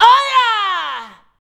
OHJAA.wav